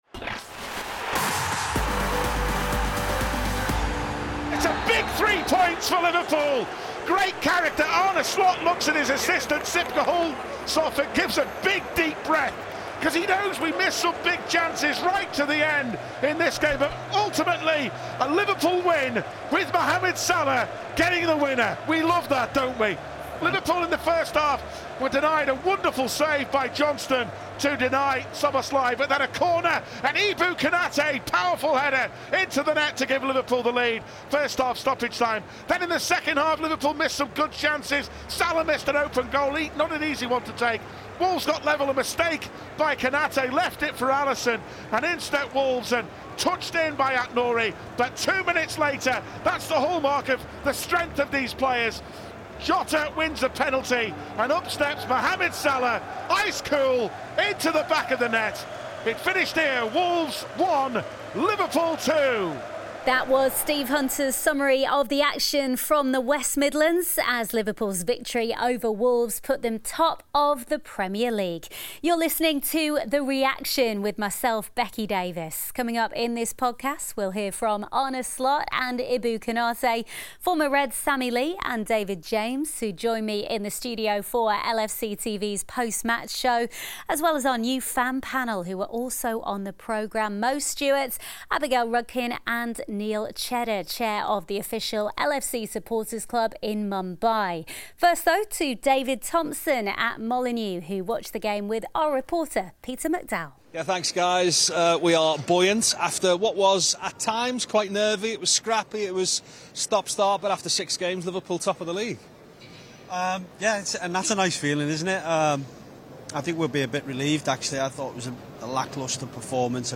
Arne Slot and goalscorer Ibrahima Konate reflect on Liverpool’s 2-1 win at Wolves which saw them go top of the Premier League. Konate’s header and Mo Salah’s penalty helped the Reds pick up all three points as they maintained their 100% away record in 2024-25 so far.